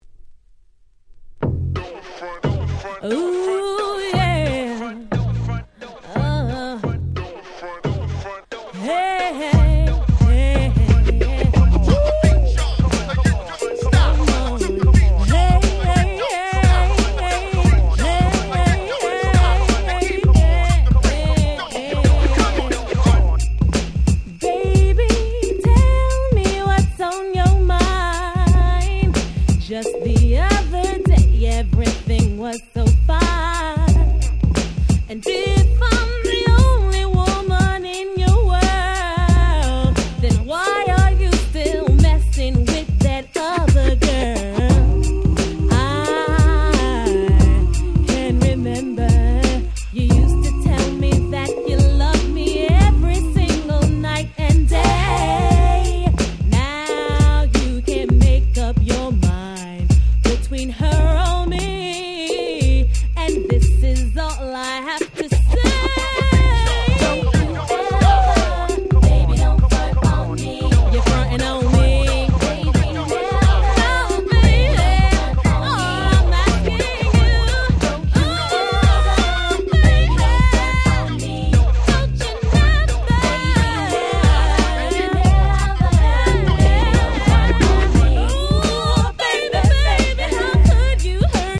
94' R&B Classics !!
ヒップホップソウル